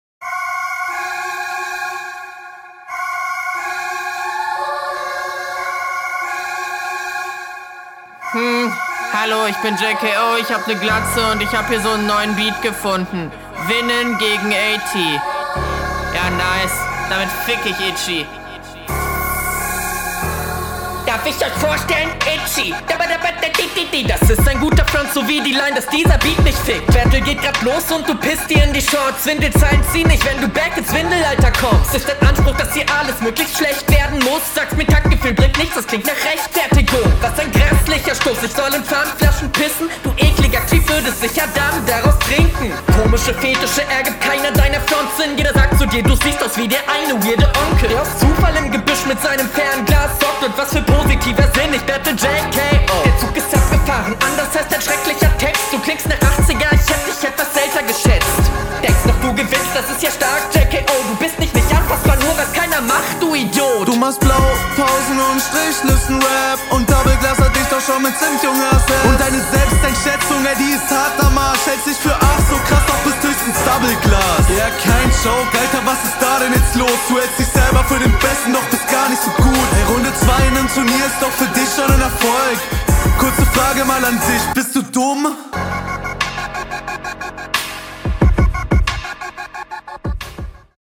Audio ist super, der rappt sehr cool und die Qualität ist gut.